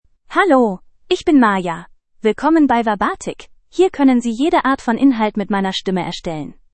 Maya — Female German (Germany) AI Voice | TTS, Voice Cloning & Video | Verbatik AI
Maya is a female AI voice for German (Germany).
Listen to Maya's female German voice.
Female
Maya delivers clear pronunciation with authentic Germany German intonation, making your content sound professionally produced.